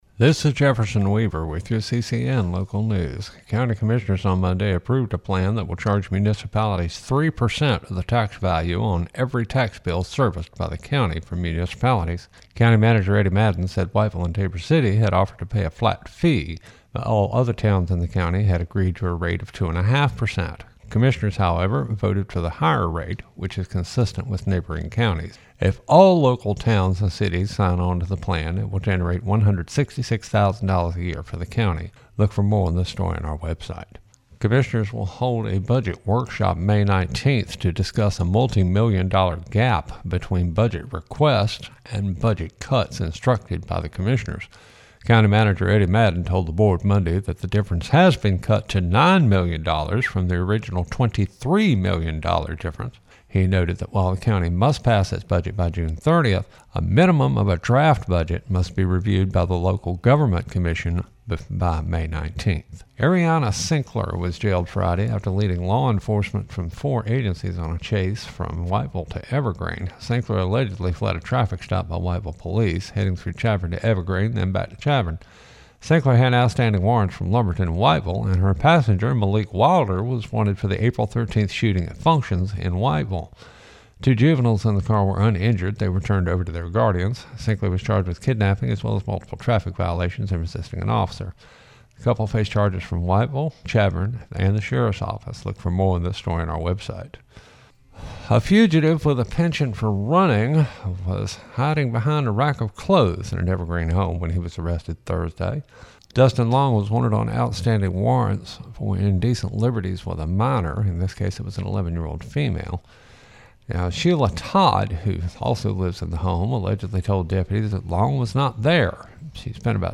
CCN Radio News — Afternoon Report for May 6, 2025
CCN-AFTERNOON-NEWS-REPORT-3.mp3